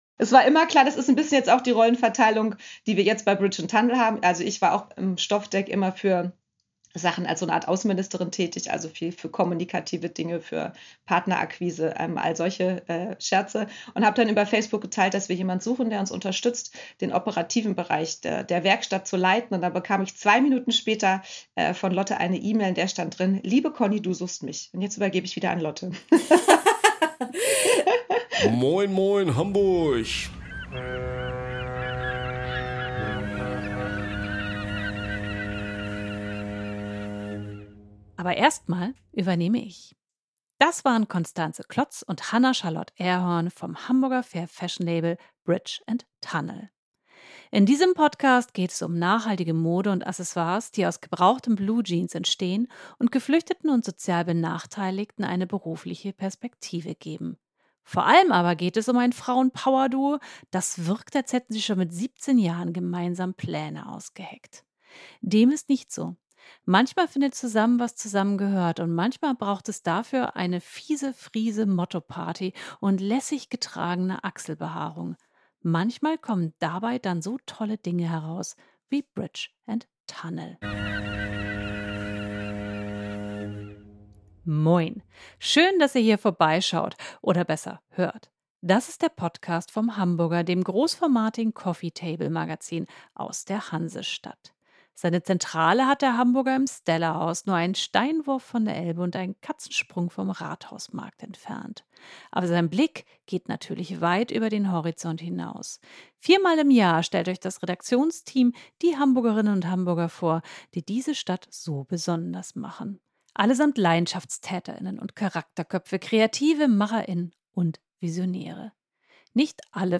Wir haben das Frauen-Power-Duo zum Podcast-Talk gebeten. Digital, das Gespräch fand zur Zeit des Lockdowns statt.